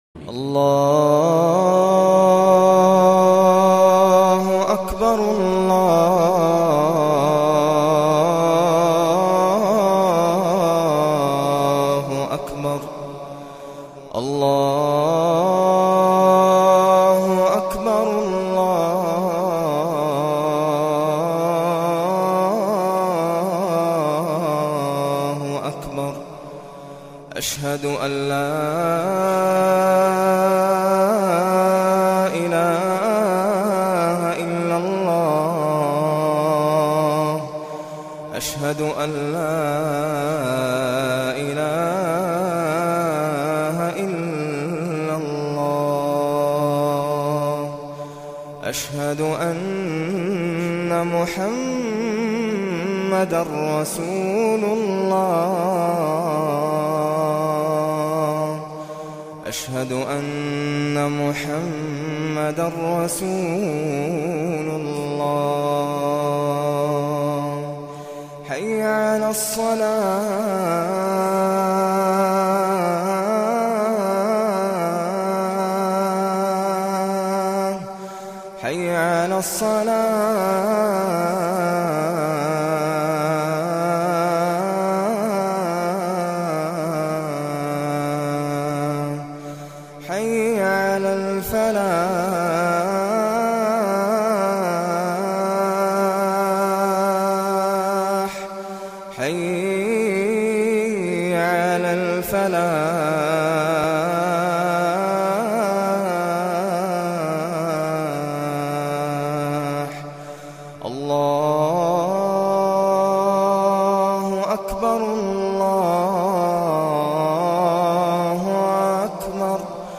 أناشيد ونغمات
عنوان المادة أذان ناصر القطامي